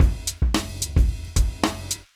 110LOOP B9-R.wav